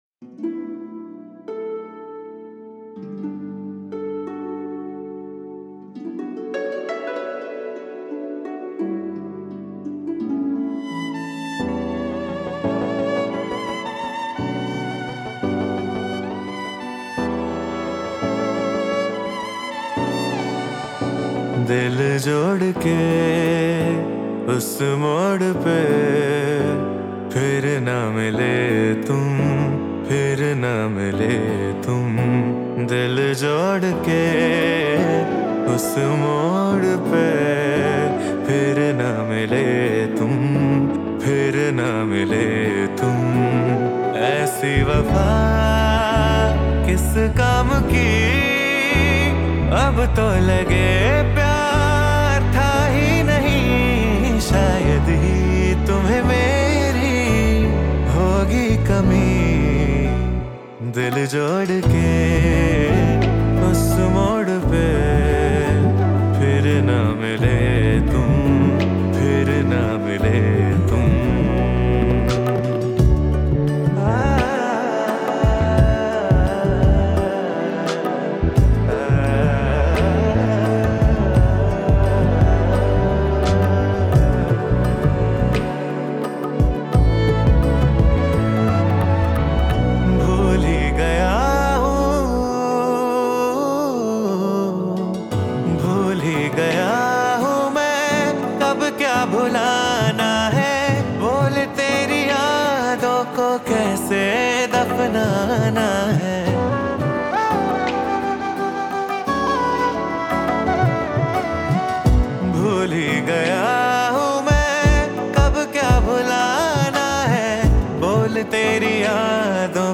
IndiPop